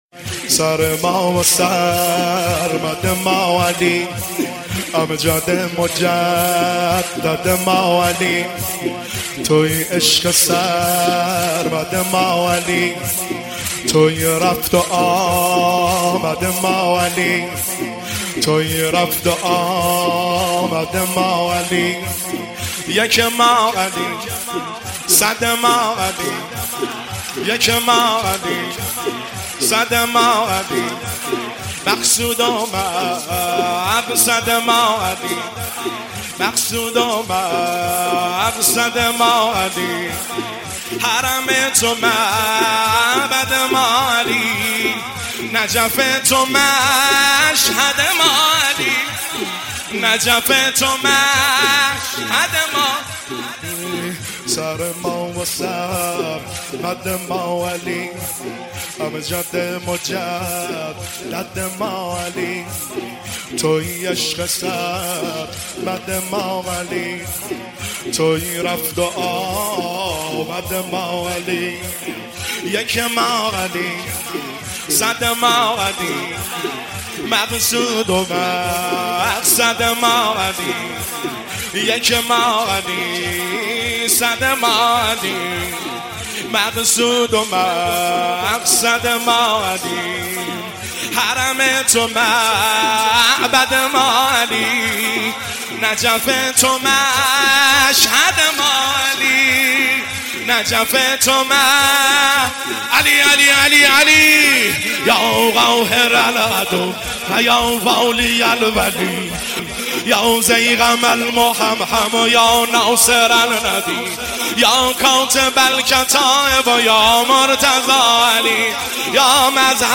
تک شب 21 رمضان المبارک 1403
هیئت بین الحرمین طهران